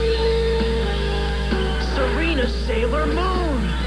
These are all origional sounds that we recorded ourselves, so please be so kind as to not steal them.